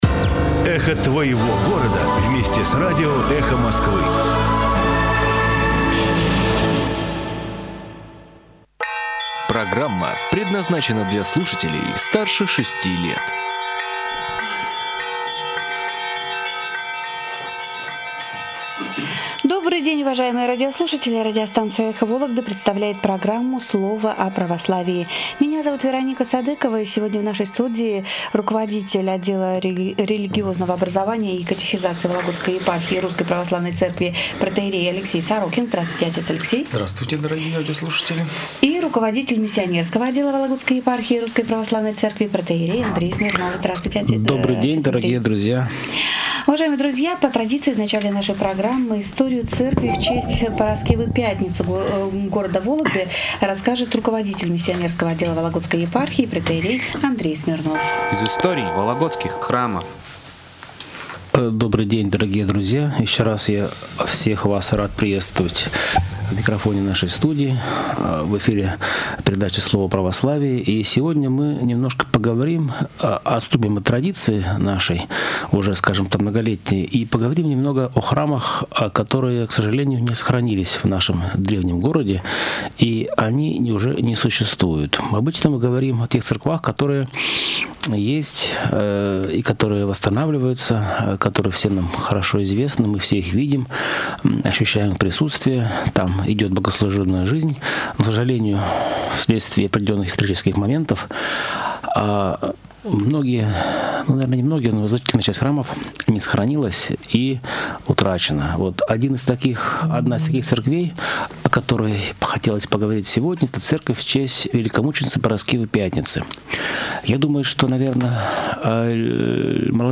Священники Вологодской епархии в эфире радио "Эхо Вологды". Слово о Православии